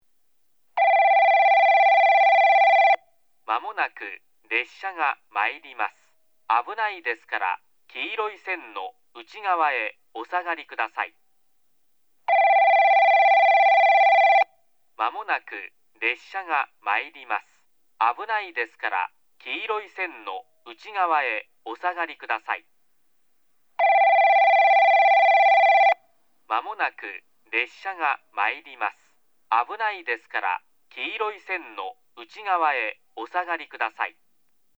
接近放送　男声